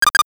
NOTIFICATION_8bit_03_mono.wav